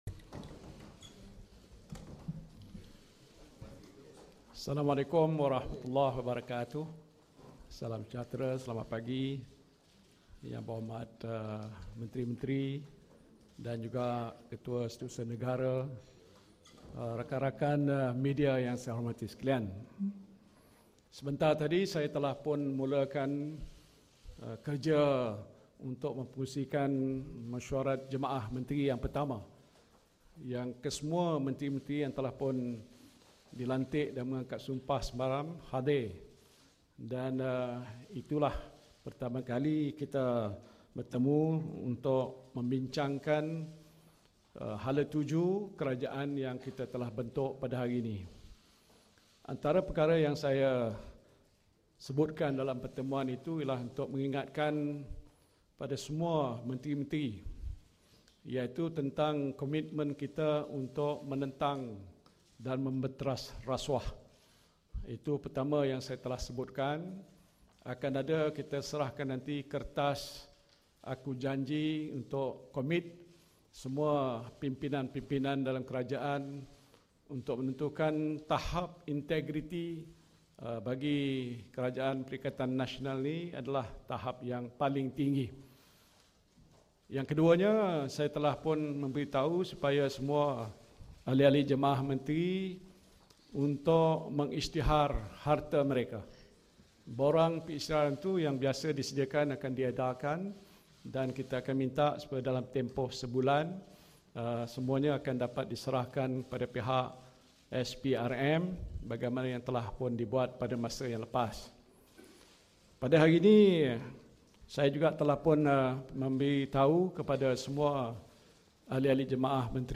Sidang Media Pasca Mesyuarat Kabinet pertama
Kita ikuti sidang media pasca Mesyuarat Kabinet pertama oleh Perdana Menteri Tan Sri Muhyiddin Yassin.